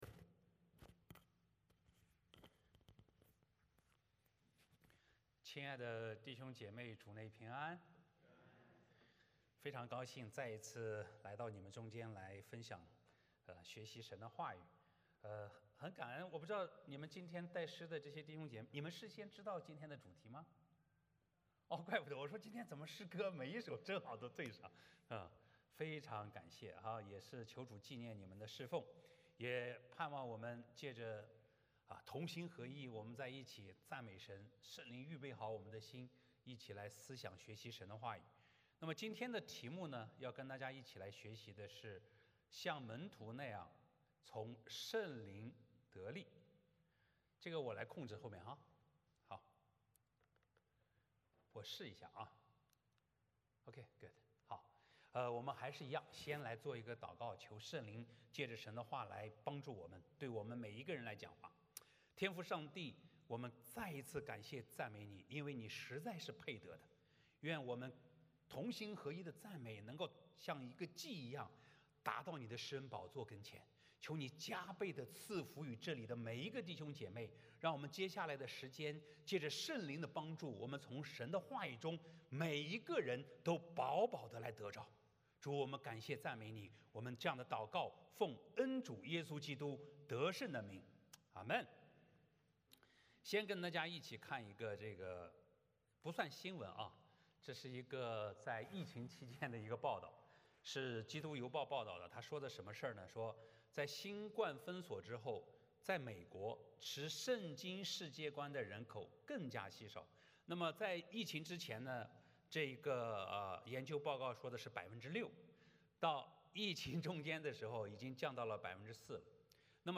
41-47 Service Type: 主日崇拜 欢迎大家加入我们的敬拜。